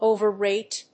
音節o･ver･rate発音記号・読み方òʊvəréɪt
• / ˌovɝˈret(米国英語)
• / ˌəʊvɜ:ˈreɪt(英国英語)